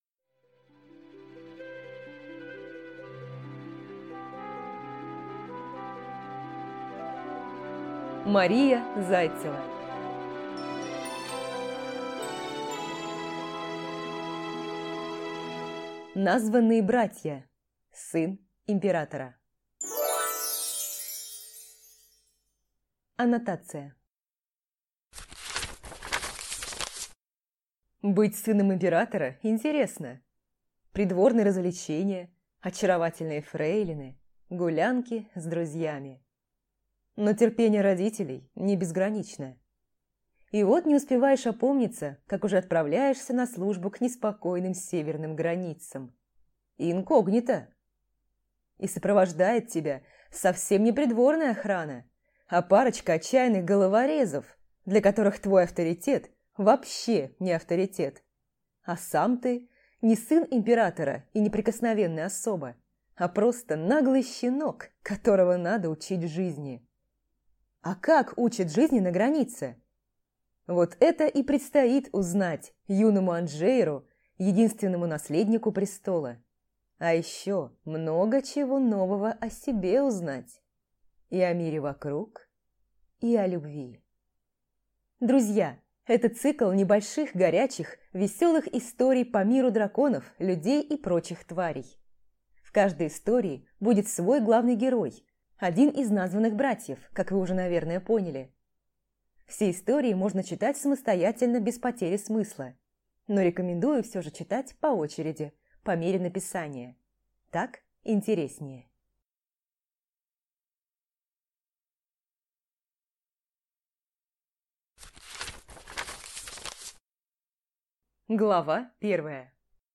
Аудиокнига Названые братья. Сын императора | Библиотека аудиокниг
Прослушать и бесплатно скачать фрагмент аудиокниги